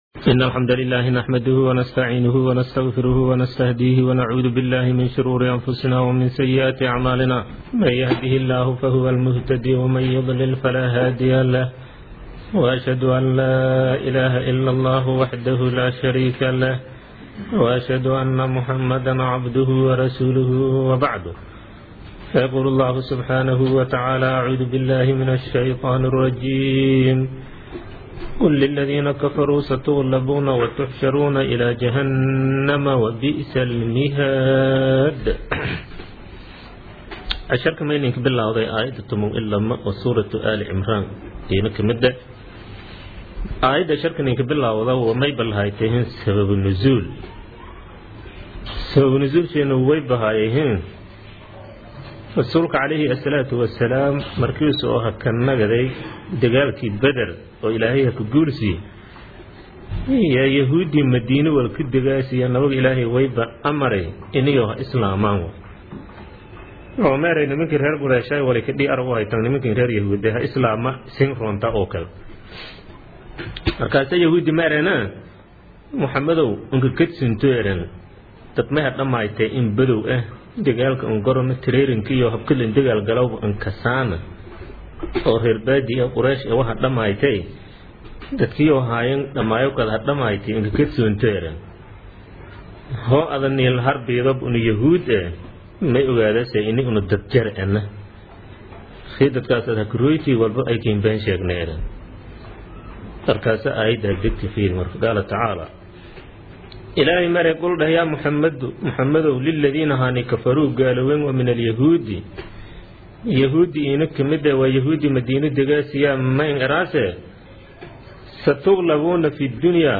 Casharka Tafsiirka Maay 40aad
Casharka-Tafsiirka-Maay-40aad.mp3